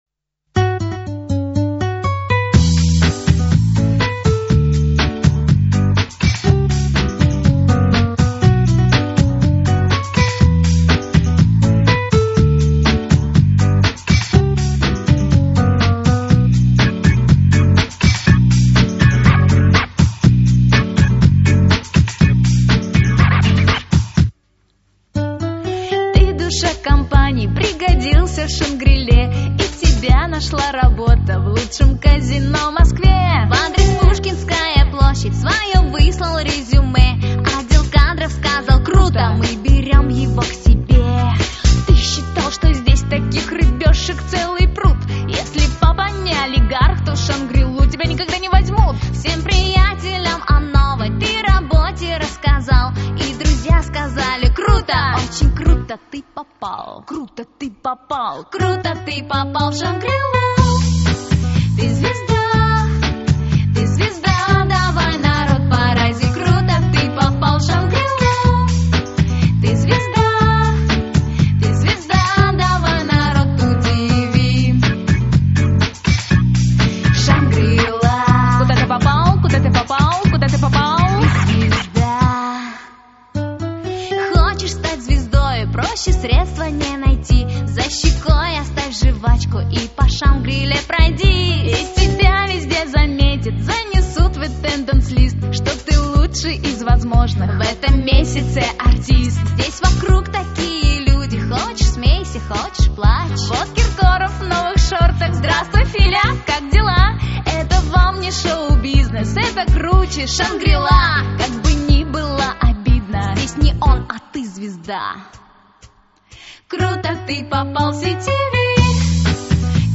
А на мой взгляд - попса...